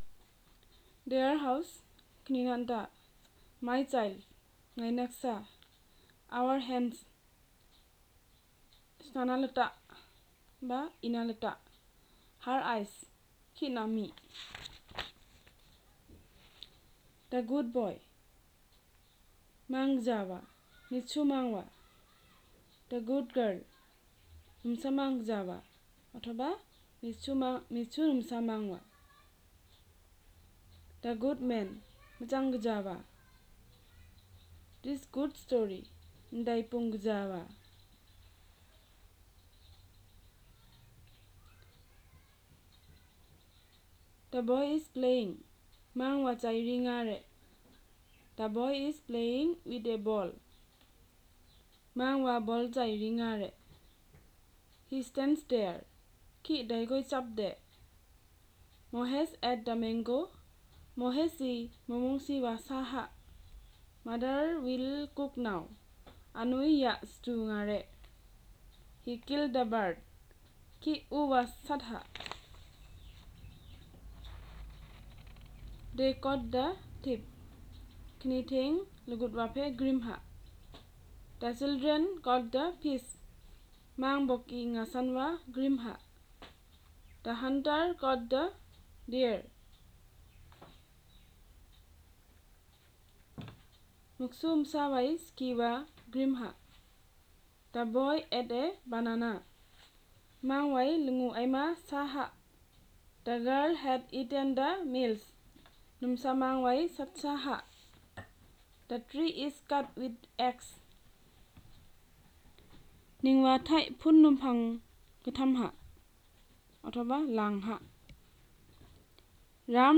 Elicitation of pronouns, noun phrases, and simple sentences